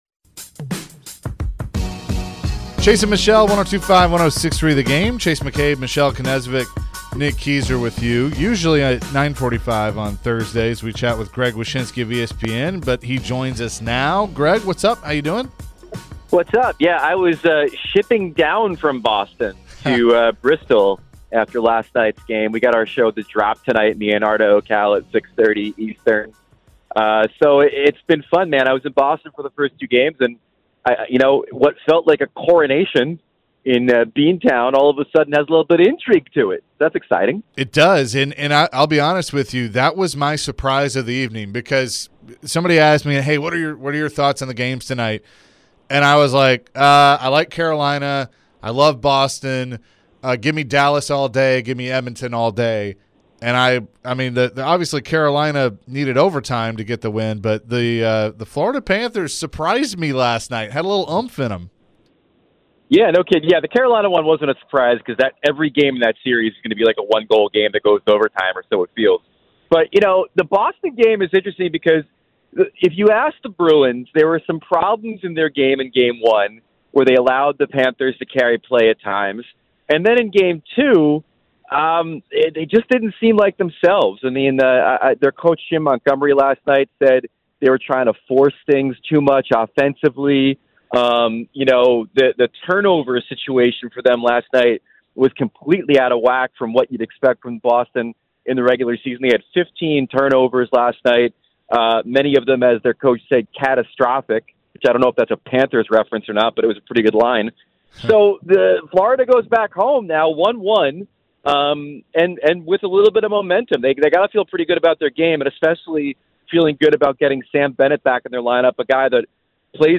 Greg Wyshynski Interview (4-20-23)